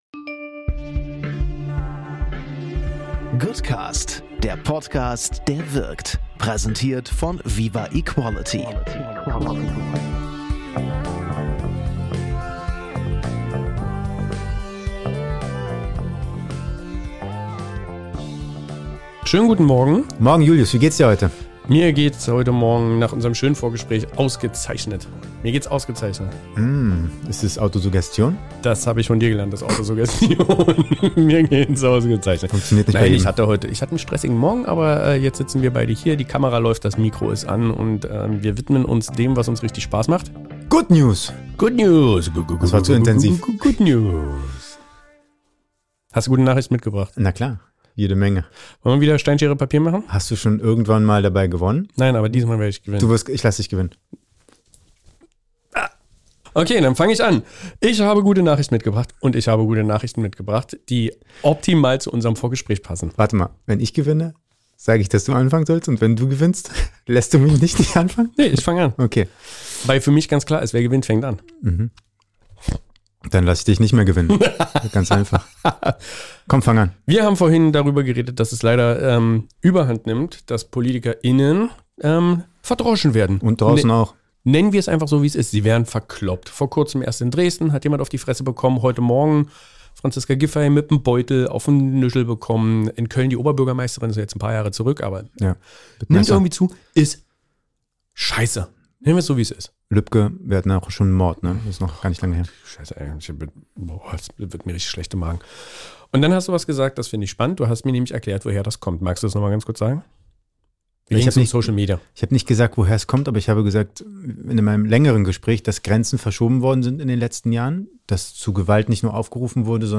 In einer lebhaften Unterhaltung